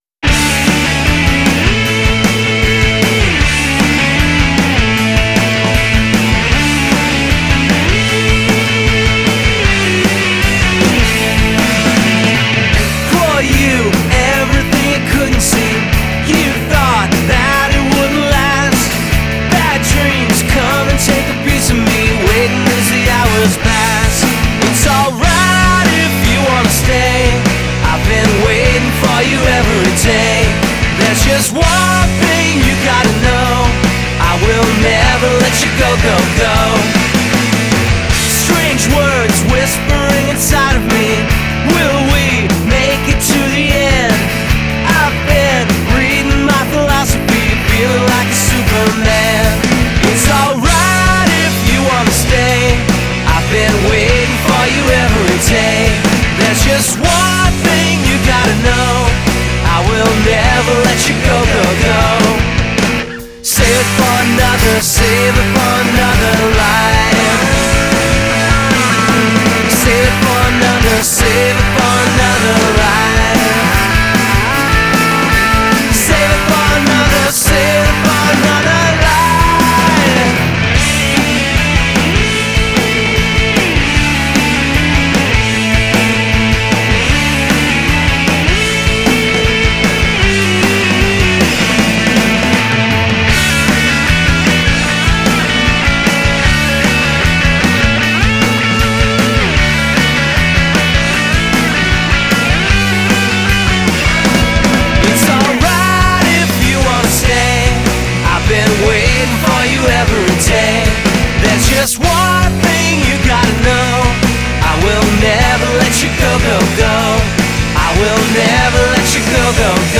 the more surging rocking